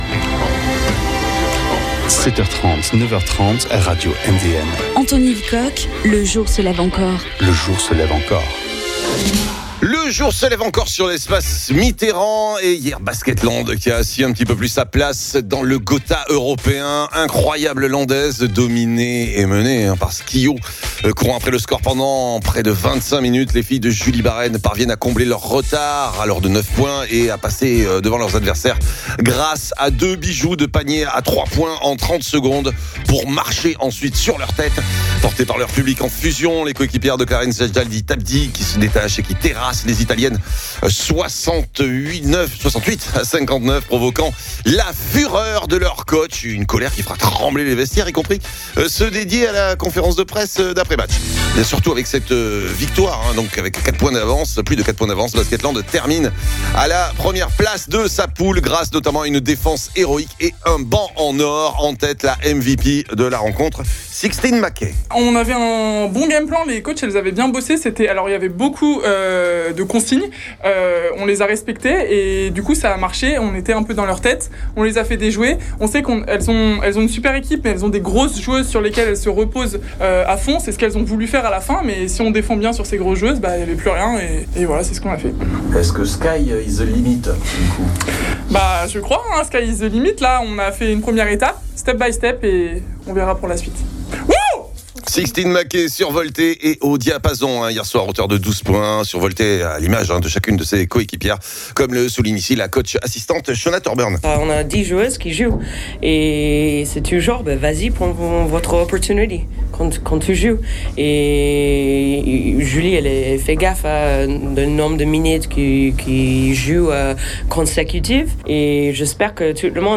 Réactions d’après match